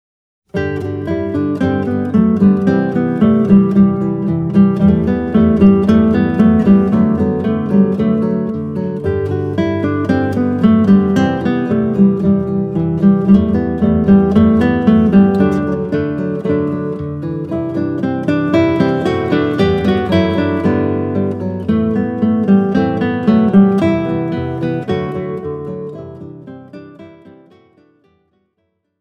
Gattung: für drei oder vier Gitarren